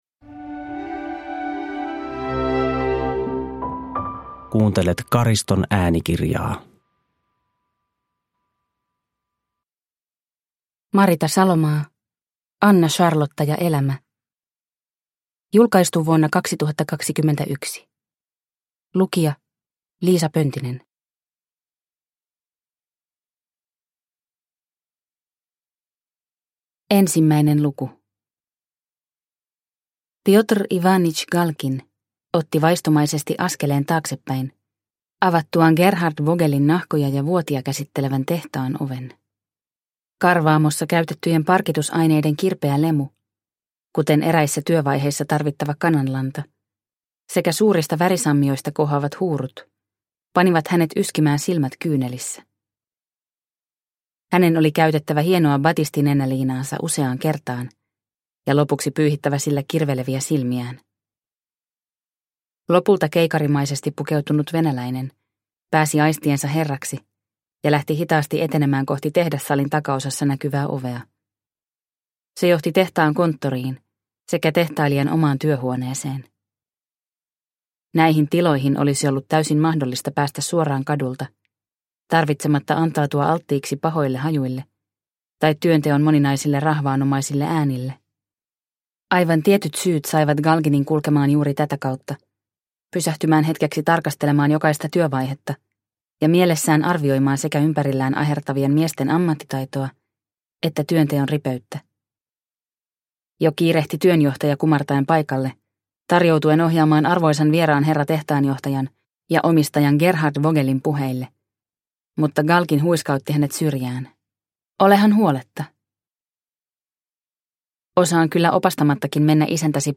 Anna Charlotta ja elämä – Ljudbok – Laddas ner